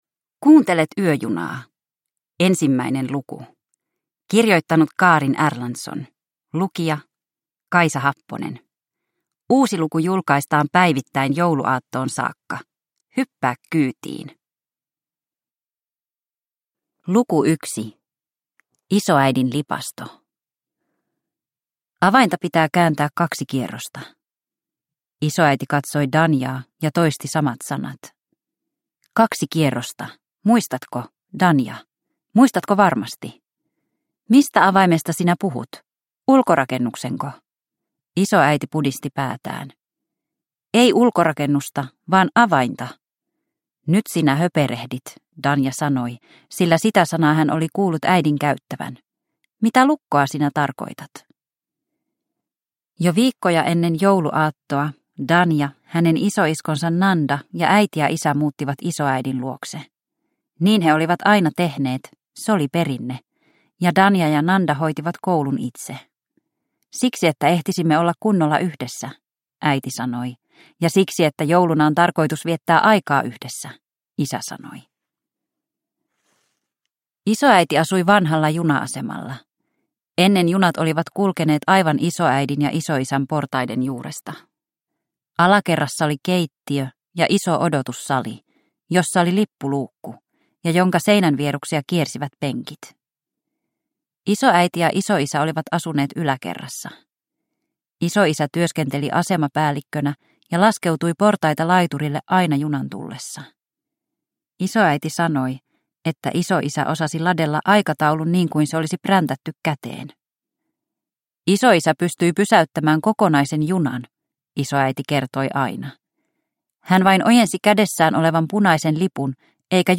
Yöjuna luku 1 – Ljudbok